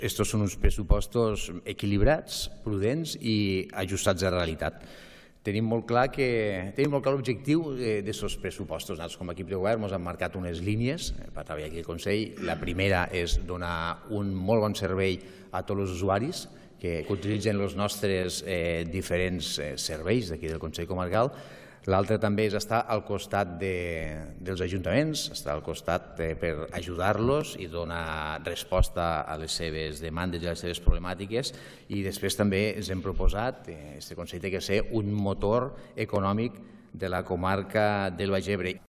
El president del Consell Comarcal, Antoni Gilabert ha descrit com “equilibrat, prudent i ajustat a la realitat el pressupost…